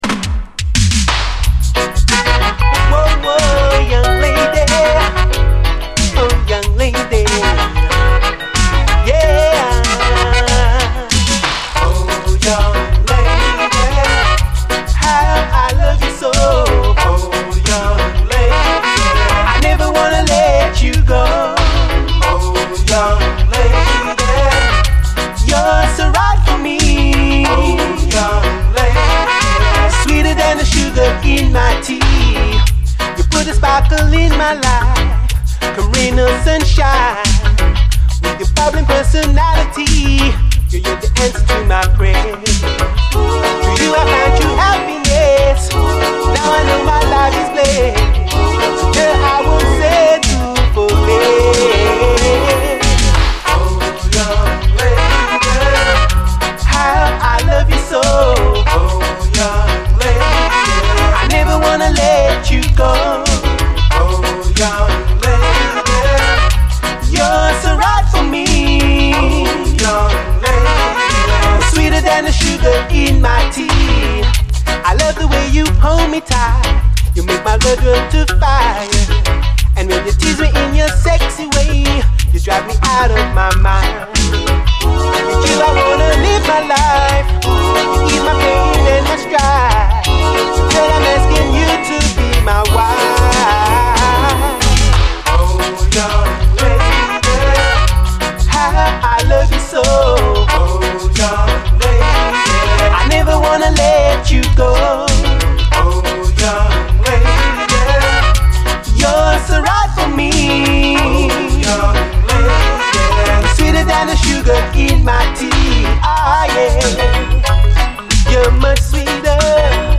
REGGAE
ゴキゲンなデジタル・ラヴァーズ！